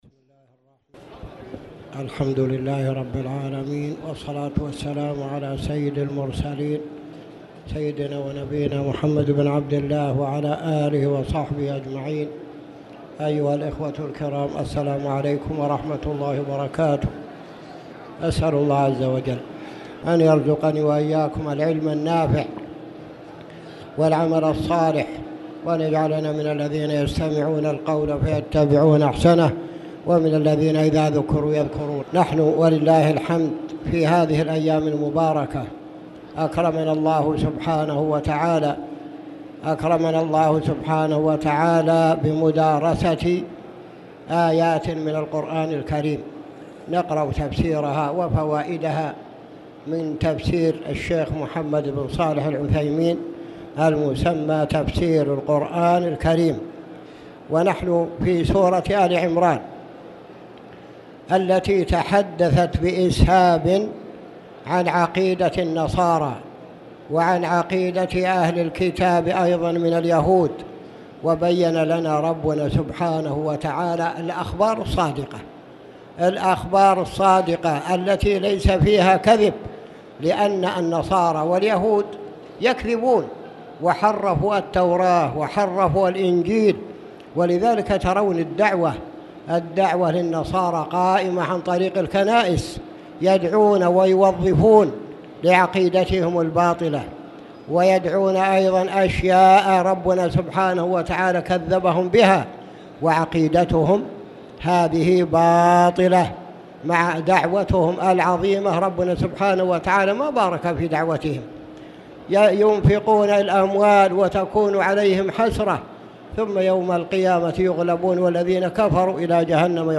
تاريخ النشر ٩ رمضان ١٤٣٨ هـ المكان: المسجد الحرام الشيخ